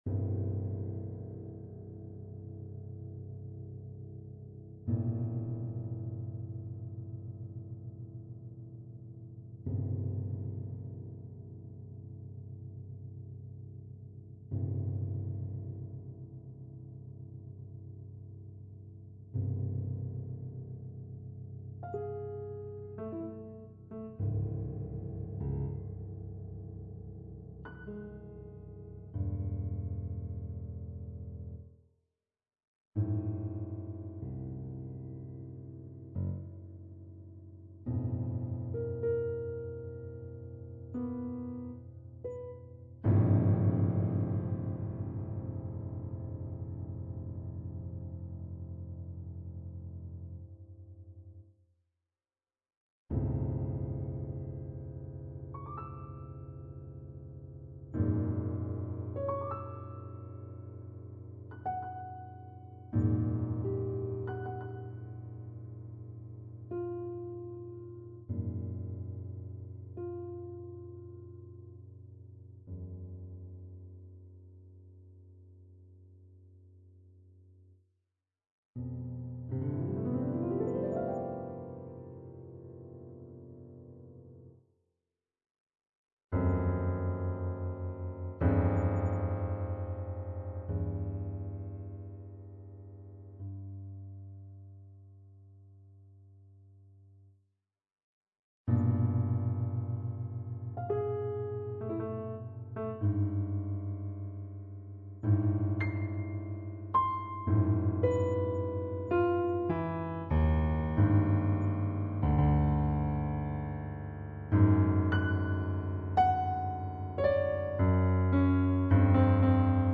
Instrument(s): piano solo.